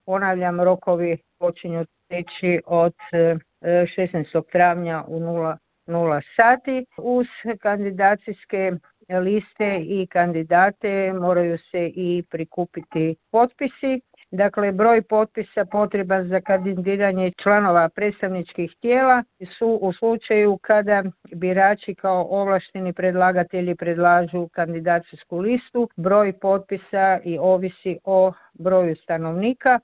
Rokovi počinju teći od 16. travnja u ponoć, a prijedlozi kandidacijskih lista i kandidatura potom moraju prispjeti nadležnom izbornom povjerenstvu u roku od 14 dana od dana stupanja na snagu Odluke o raspisivanju izbora, rekla je u razgovoru za Media servis potpredsjednica Državnog izbornog povjerenstva Vesna Fabijančić Križanić.